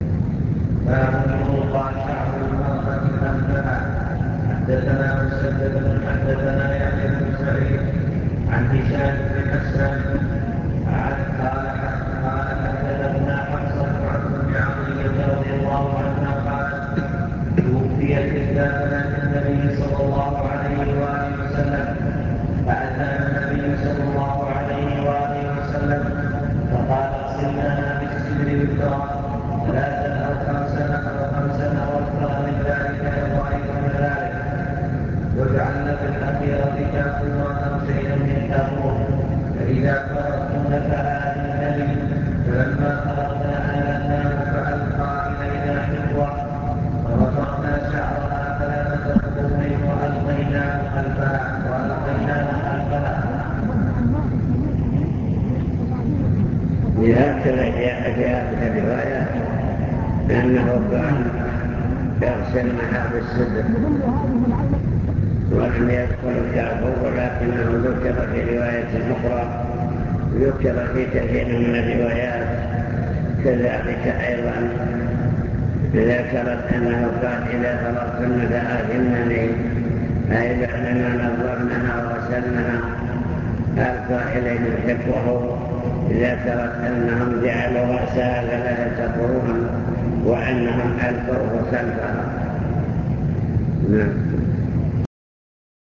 المكتبة الصوتية  تسجيلات - محاضرات ودروس  محاضرة في الزلفى مع شرح لأبواب من كتاب الجنائز في صحيح البخاري